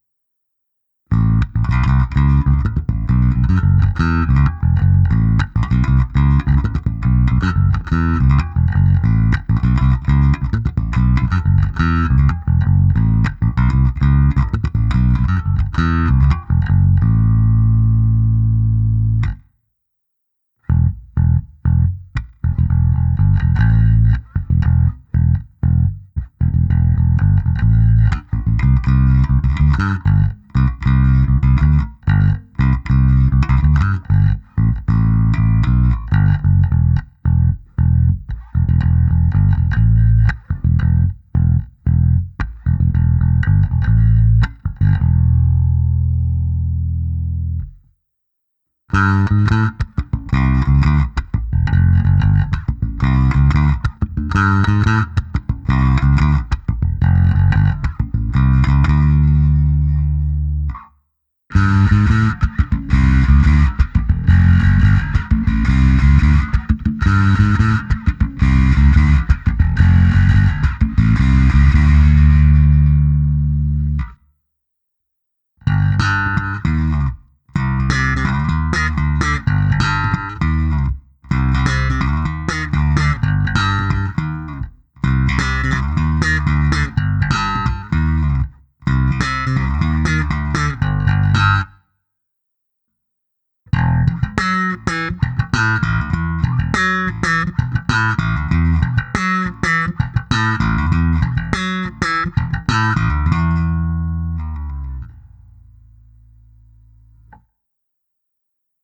Zvukově je to opravdu typický Jazz Bass se vším všudy.
Nahrávka se simulací aparátu, kde bylo použito i zkreslení a hra slapem